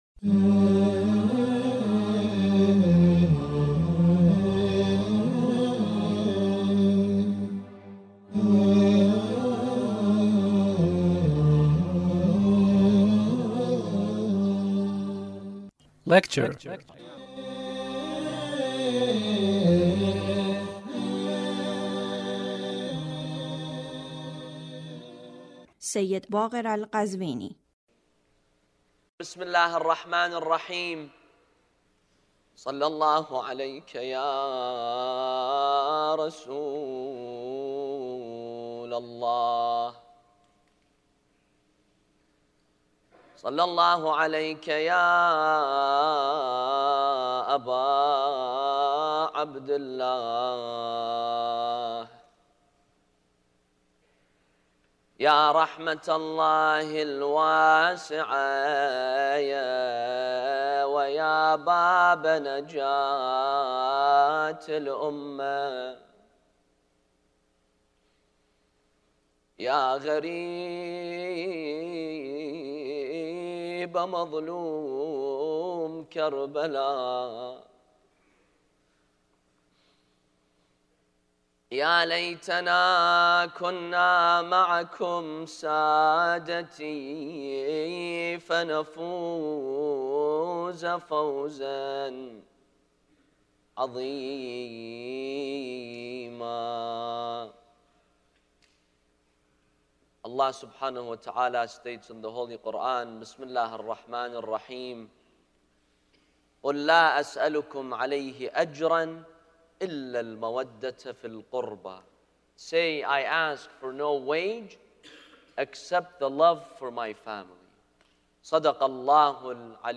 Lecture (67)